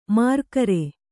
♪ mārkare